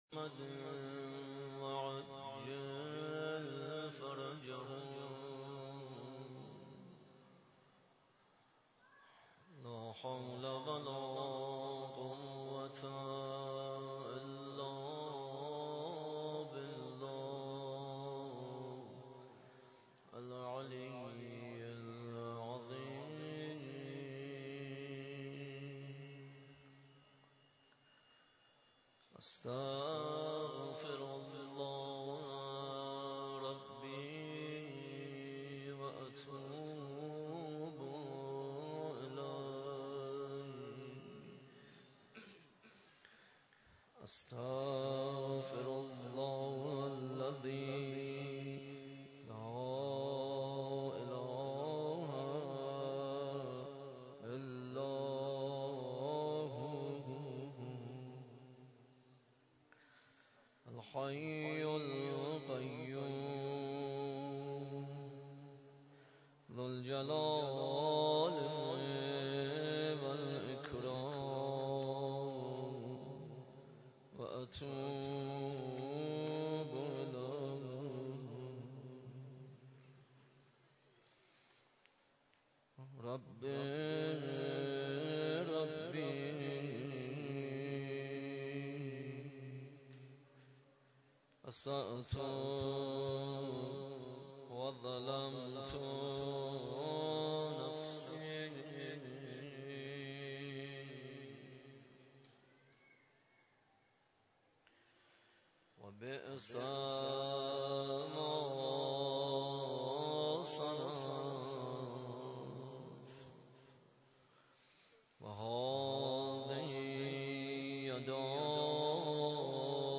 مراسم شب نوزدهم ماه مبارک رمضان
مداحی